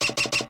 clownspiderstep1.ogg